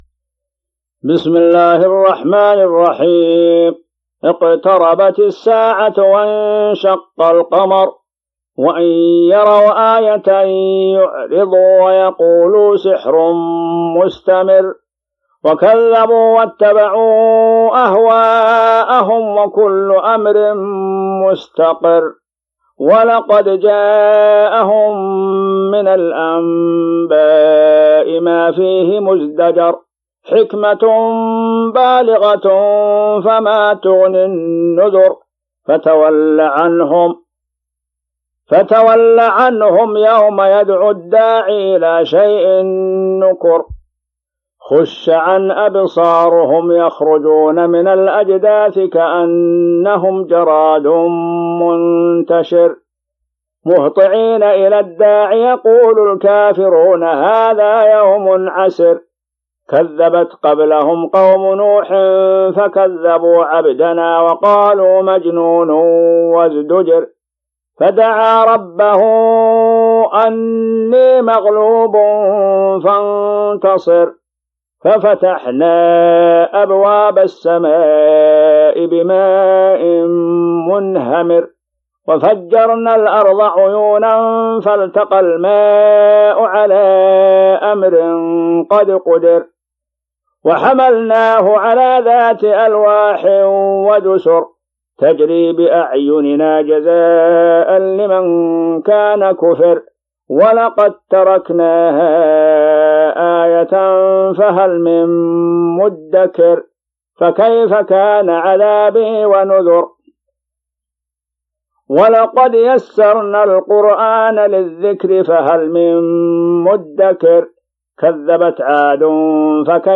تلاوات
الصنف: تلاوات
28 جوان 2017 م رواية : حفص عن عاصم الحجم:2.7M المدة